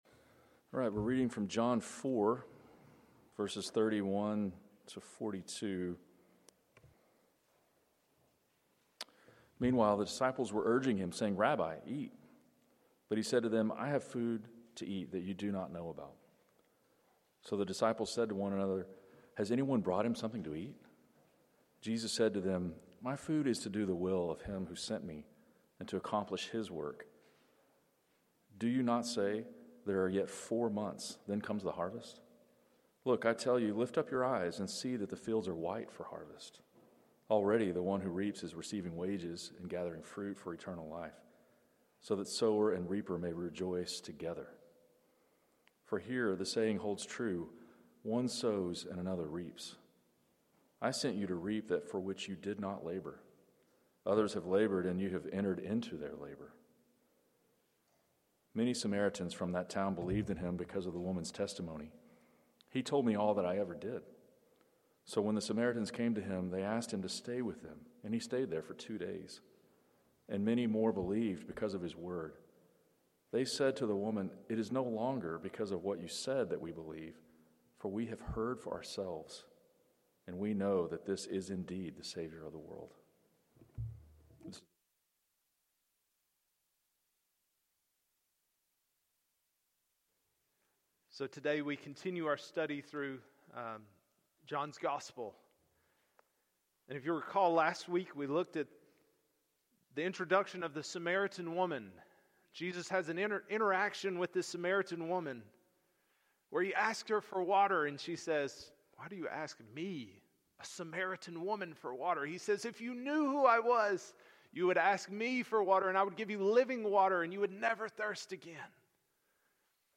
Passage: John 4:31-42 Sermon